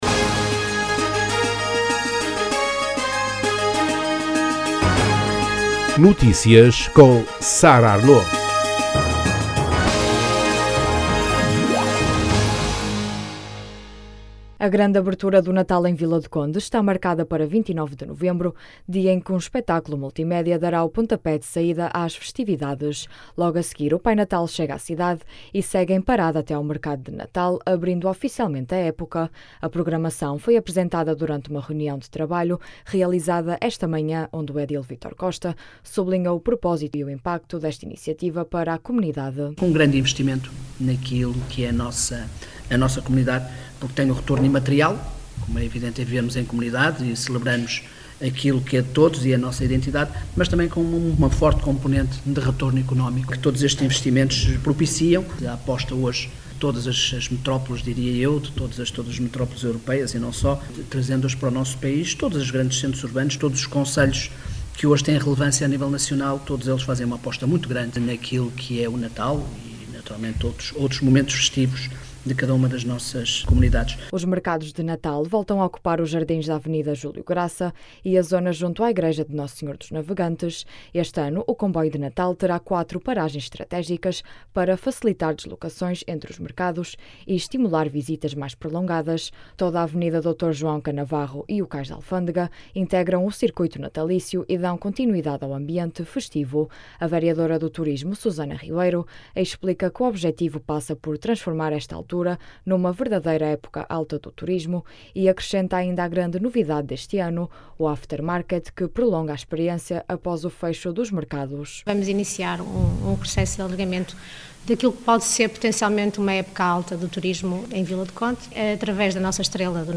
A programação foi apresentada durante um reunião de trabalho realizada esta manhã, onde o edil Vítor Costa, sublinhou o propósito e o impacto desta iniciativa para a comunidade.
As declarações podem ser ouvidas na edição local.